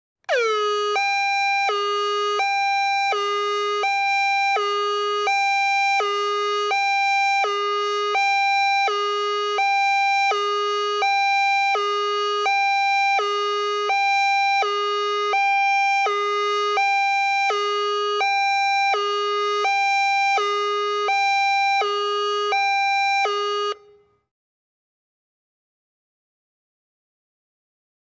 High-low Siren, Steady Close.